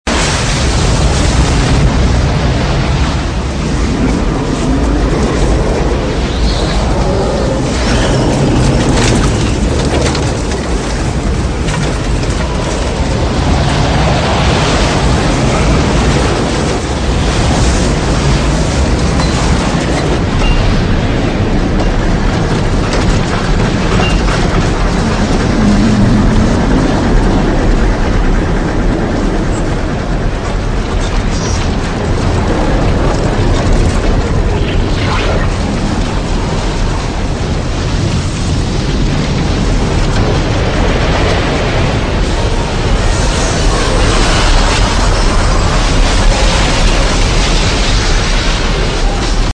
Download Tornado sound effect for free.
Tornado